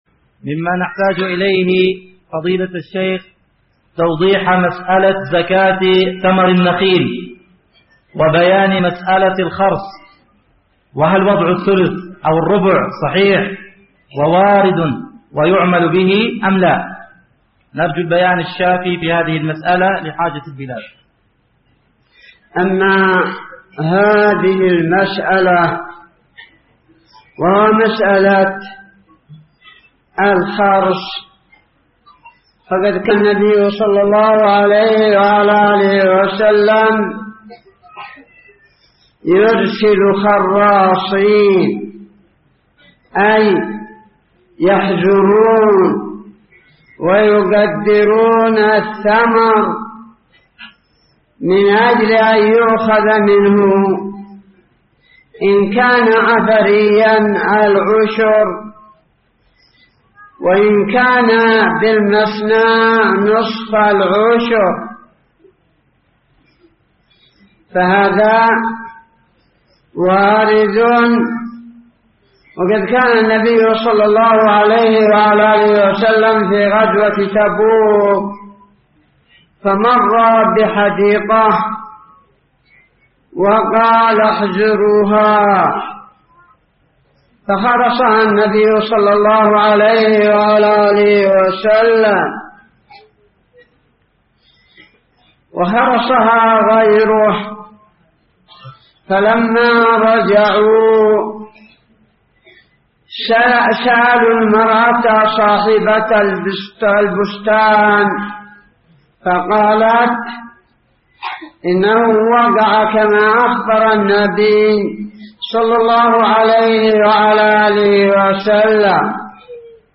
----------- من شريط : ( أسئلة أهل الريدة الشرقية بحضرموت )